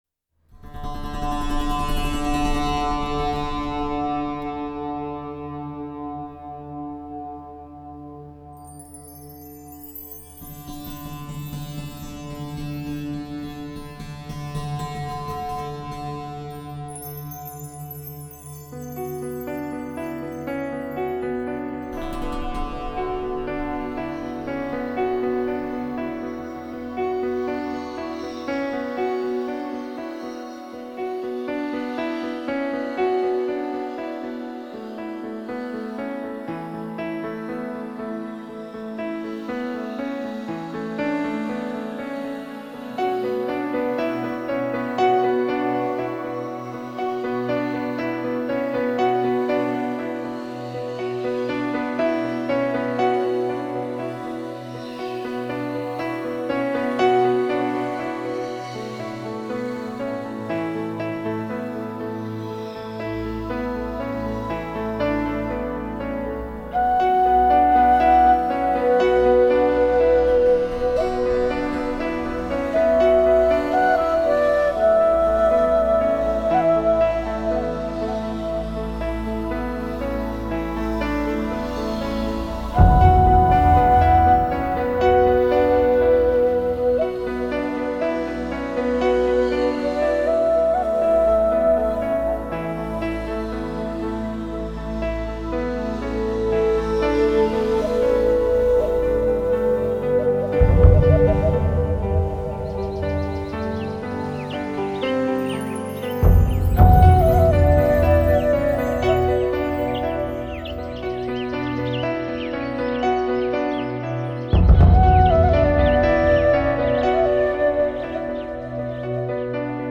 融合东西两方音乐元素，风靡欧洲的跨界音乐代表作
佐以饱满的环境音效呈现出宽阔的空间感，